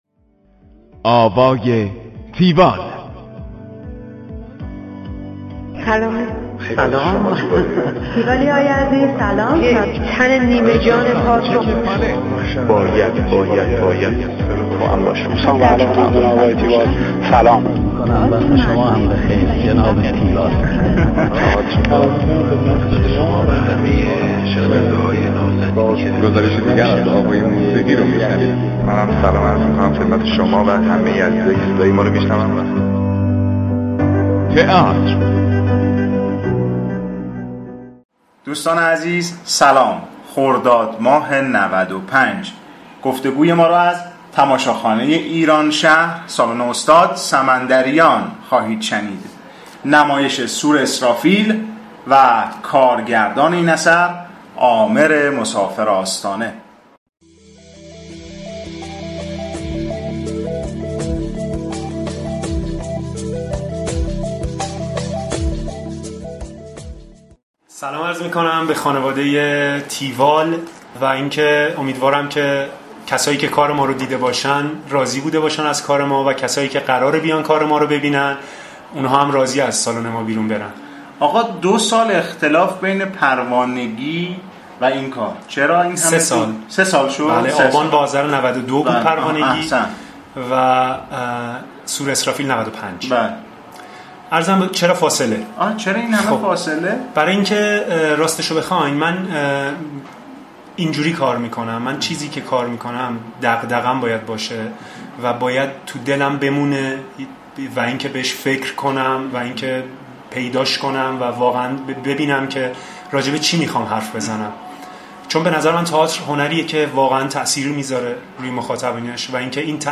دانلود فایل صوتی گفتگوی تیوال